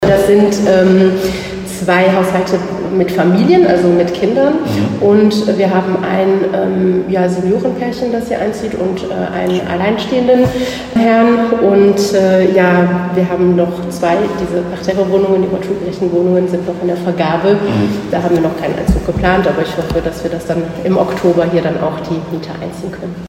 Noch hallt es in den Wohnungen, aber wie eben gehört, sind bald alle vermietet.